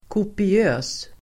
Ladda ner uttalet
Uttal: [kopi'ö:s]